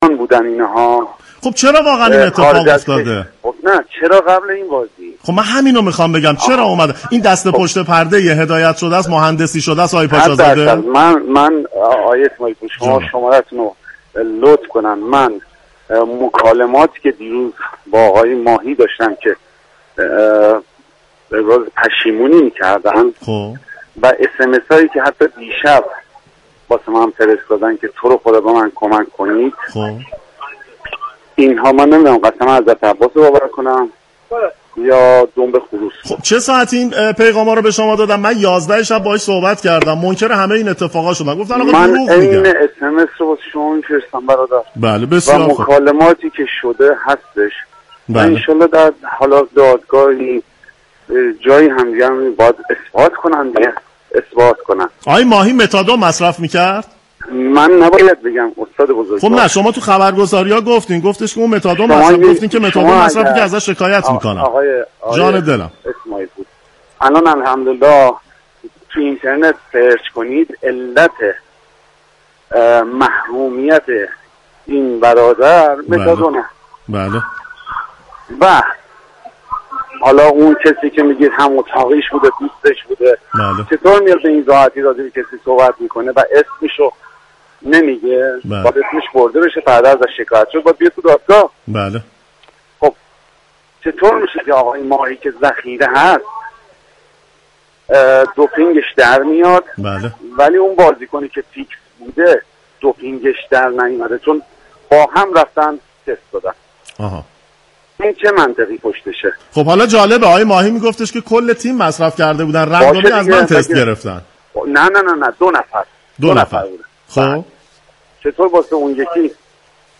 در این گفتگوی تلفنی ضمن تكذیب همۀ مباحث مطرح شده دربارۀ این موضوع گفت
كارشناس فوتبال